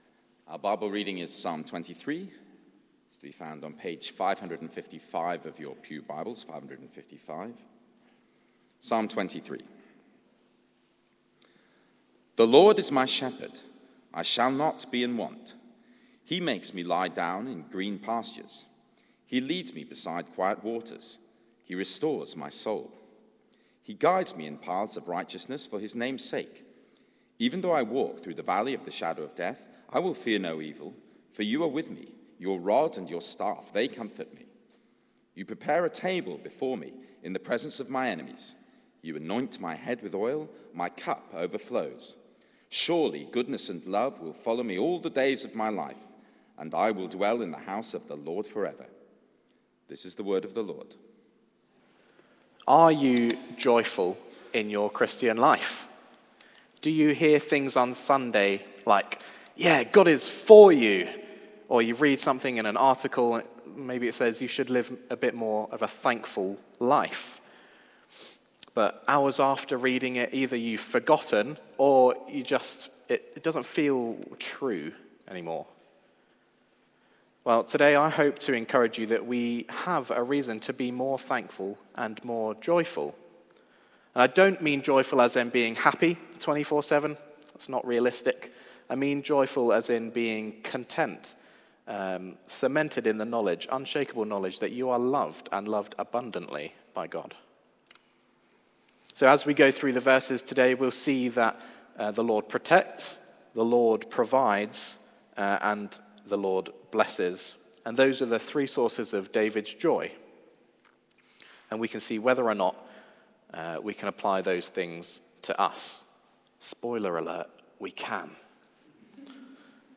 Theme: The King's Experience: The LORD is my shepherd Sermon